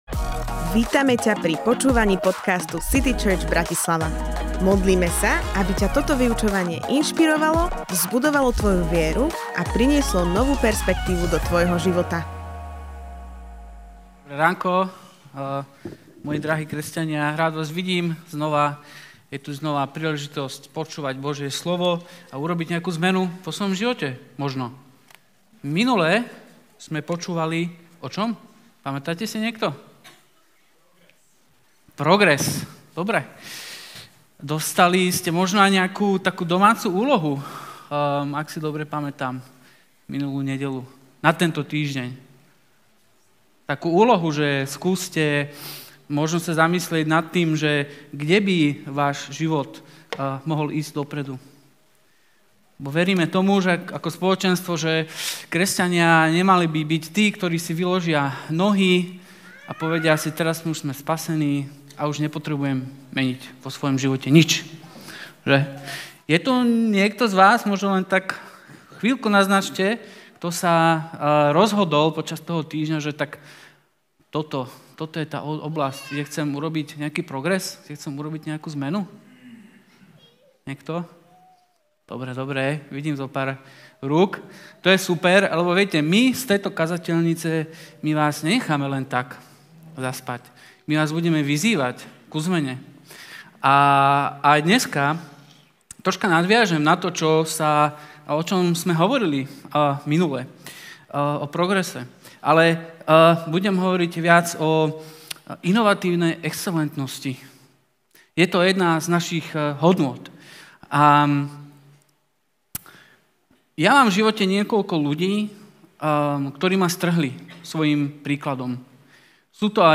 Kázeň týždňa